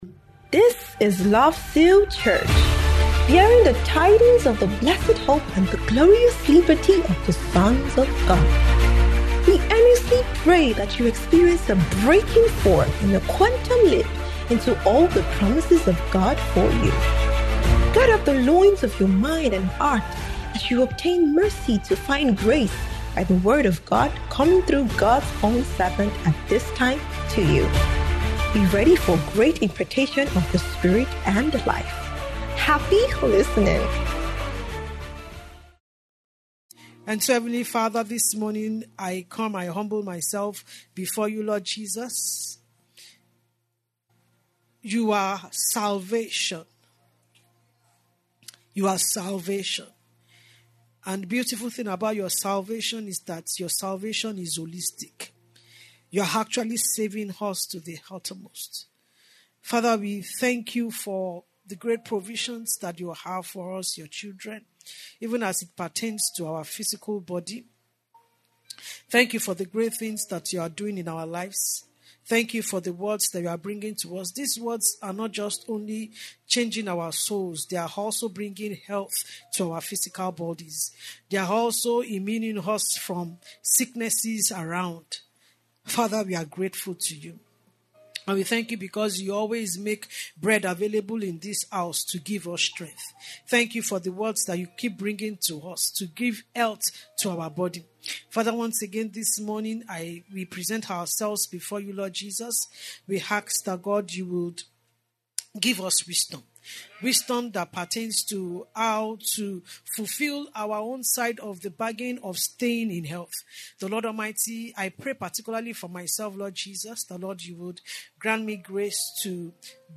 Annual Medical Retreat 2025